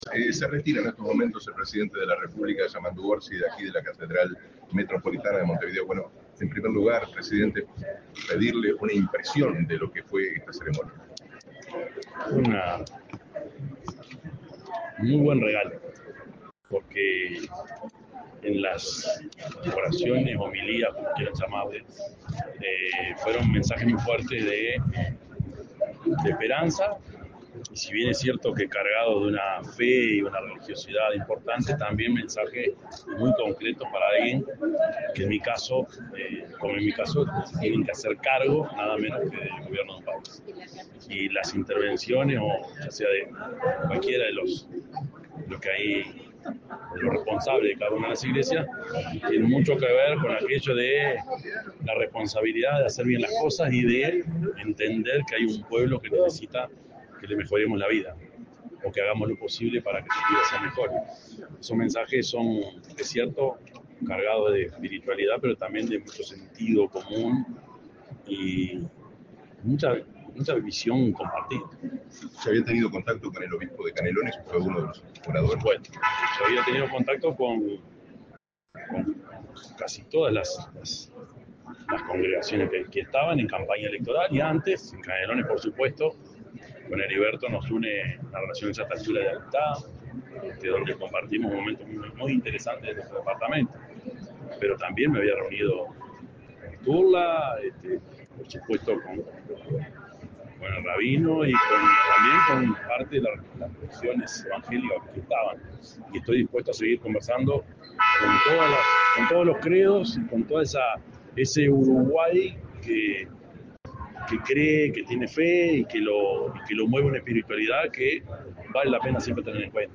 Declaraciones del presidente de la República, profesor Yamandú Orsi
Tras el encuentro, el mandatario realizó declaraciones a la prensa.